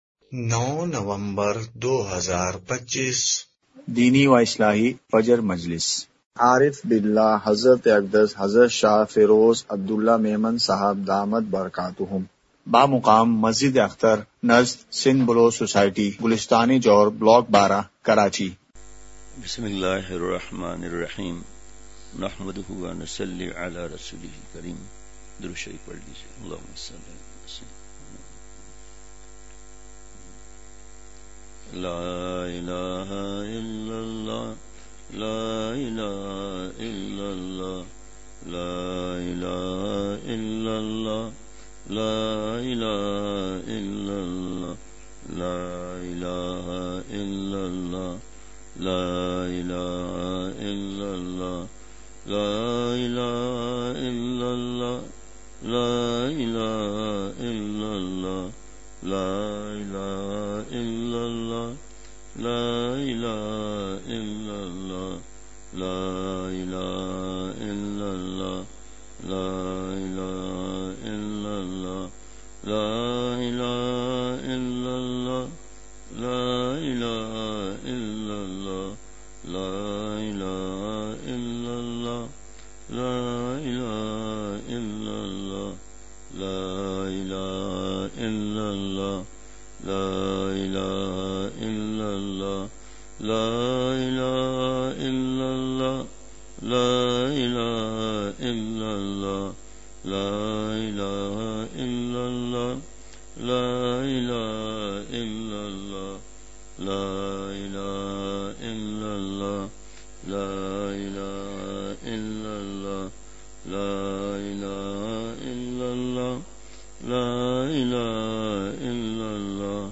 *مقام:مسجد اختر نزد سندھ بلوچ سوسائٹی گلستانِ جوہر کراچی*